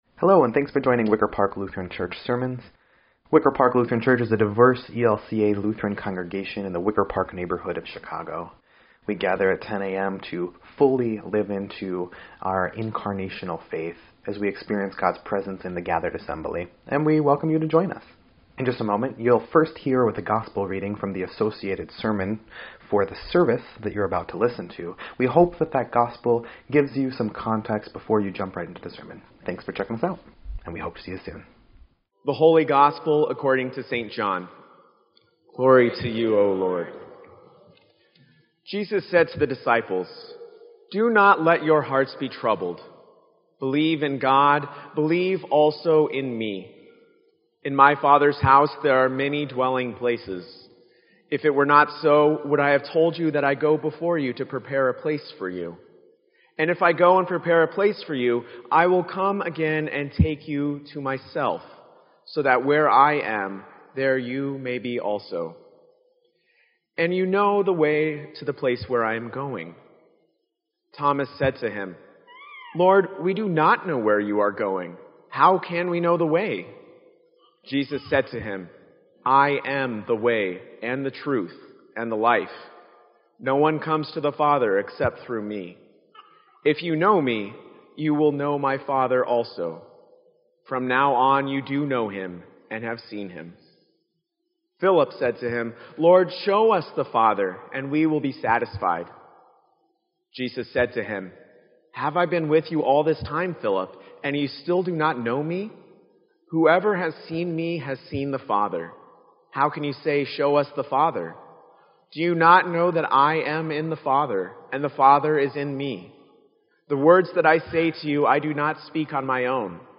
Sermon_5_14_17_EDIT.mp3